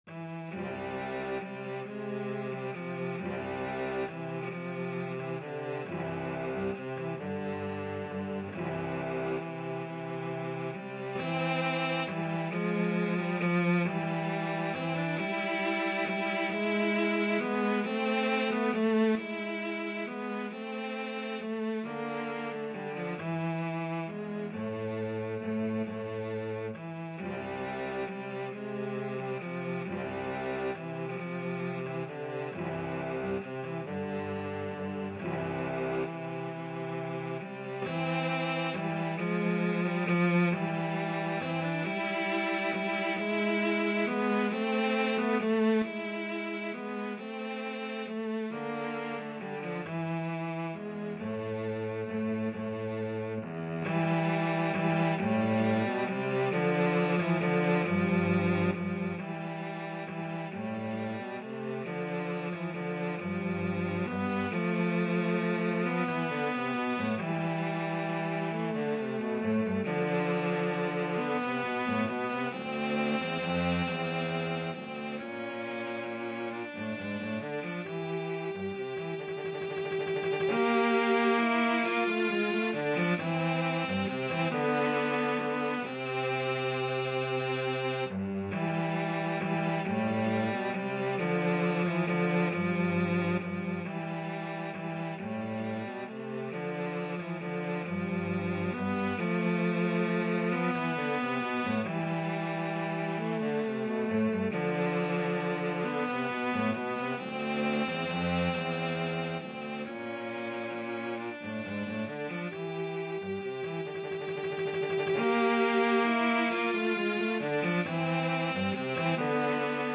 Andantino .=c.45 (View more music marked Andantino)
6/8 (View more 6/8 Music)
Cello Duet  (View more Intermediate Cello Duet Music)
Classical (View more Classical Cello Duet Music)